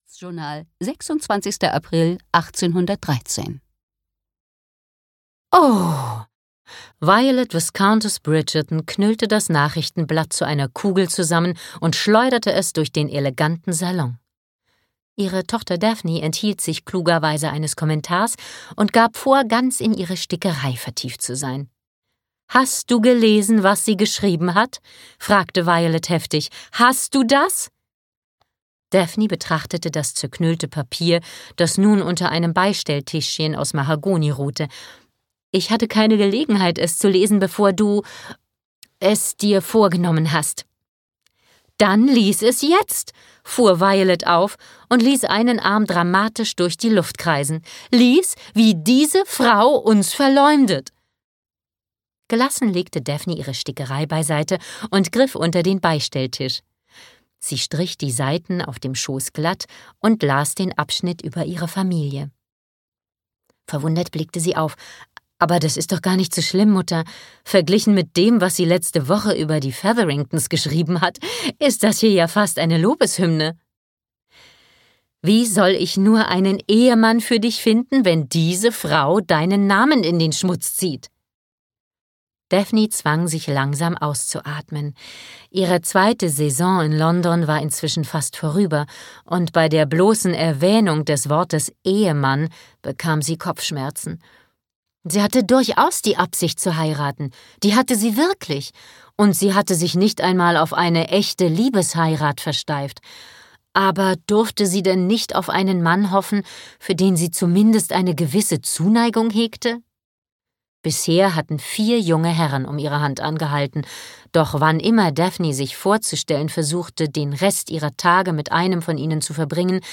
Ukázka z knihy
bridgerton-der-duke-und-ich-de-audiokniha